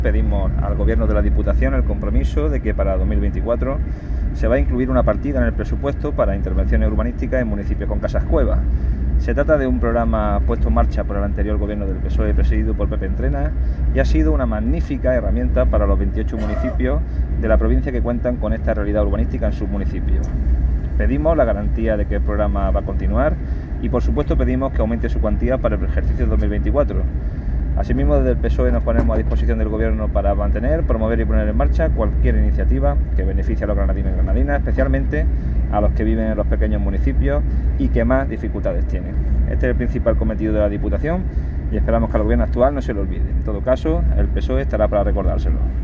Este es el titular del comunicado remitido desde la sede del PSOE granadino, acompañado de una nota de voz de Juan Francisco Torregrosa, quien afirma que se trata de una iniciativa “fundamental” para los 28 municipios de la provincia que poseen este tipo de espacios singulares, conforme al contenido íntegro de esta comunicación que reproducimos a continuación: